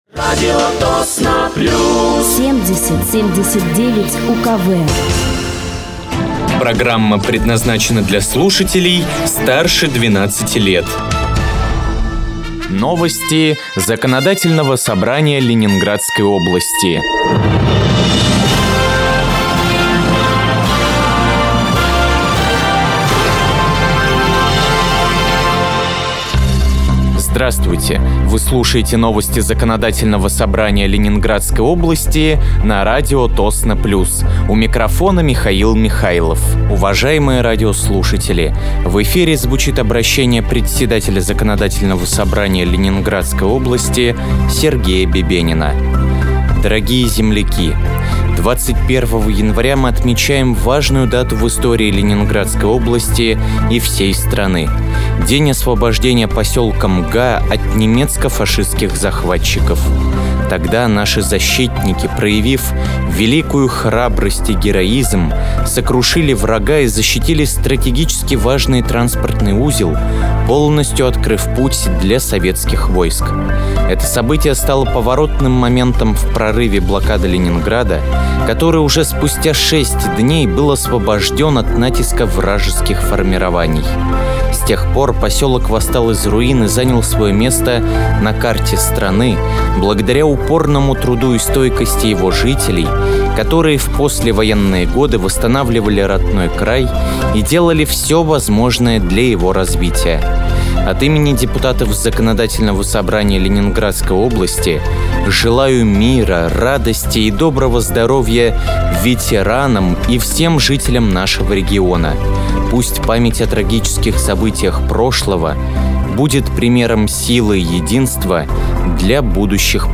Вы слушаете новости Законодательного собрания Ленинградской области от 21.01.2025 на радиоканале «Радио Тосно плюс».